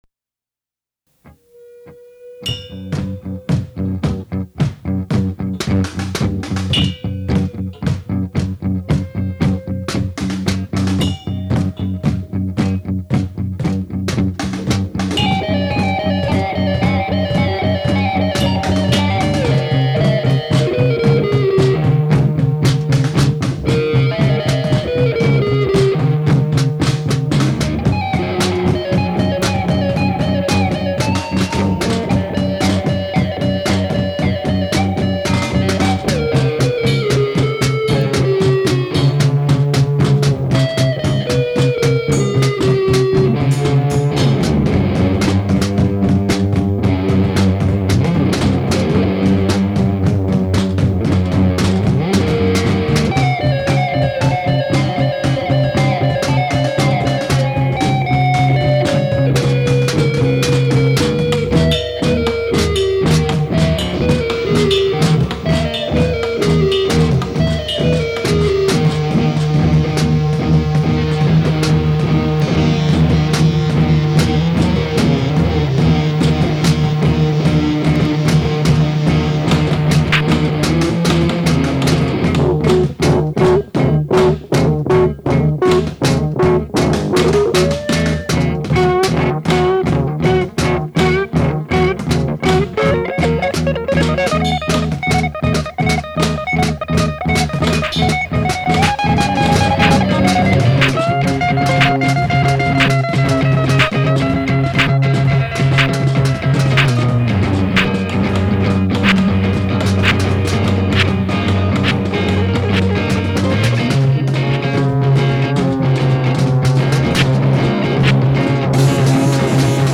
Garageol(?) recorded in the summer of 2001 in a garage in Ohio. Some pots and pans and cardboard "drums" on this.